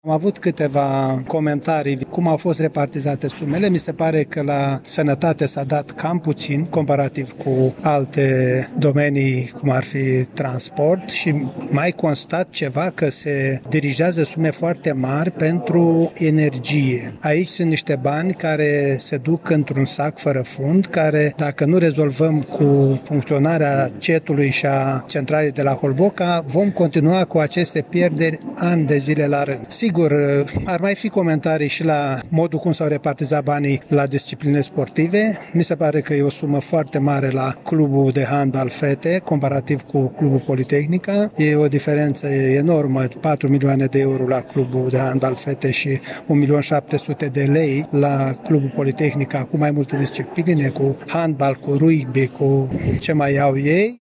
Liderul grupului AUR, Vasile Pușcaș, a declarat că o problemă o constituie moducl în care au fost repartizați banii.